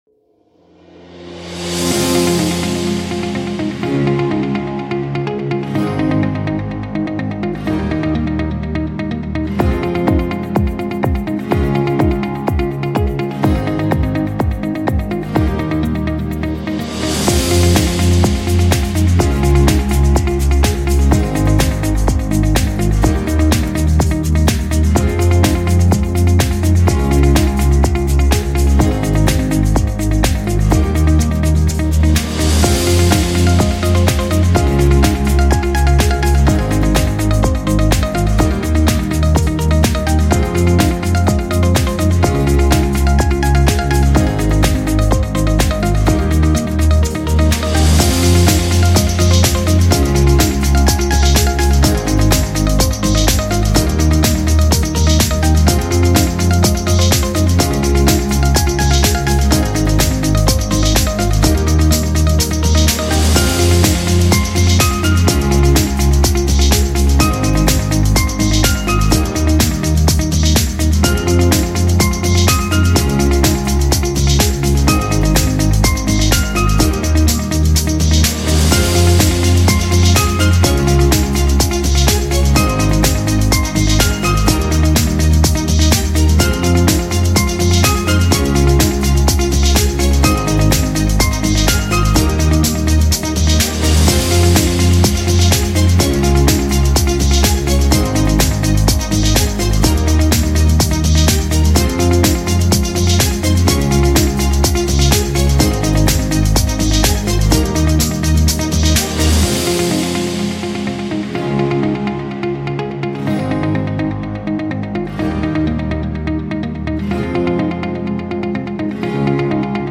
Звук для энергичных видеопроектов